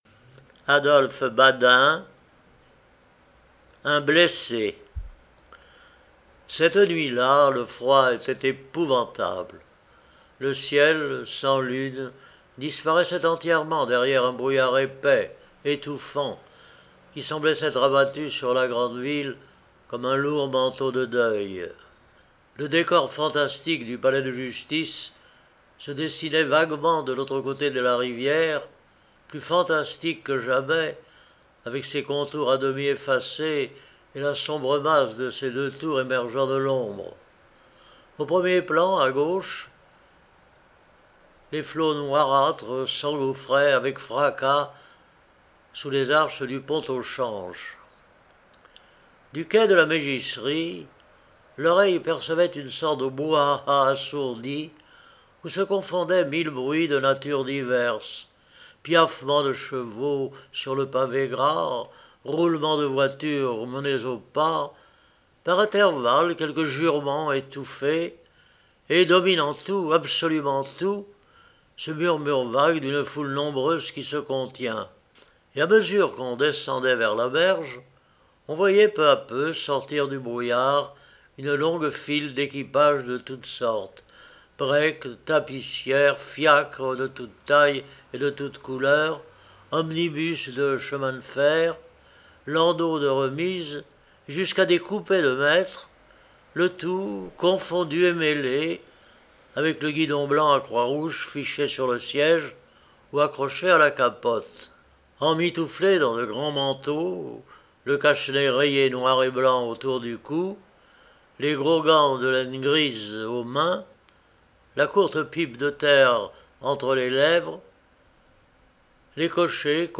BADIN Adolphe – Livre Audio !
Genre : Nouvelles « Le siège de Paris est un épisode de la guerre franco-allemande de 1870.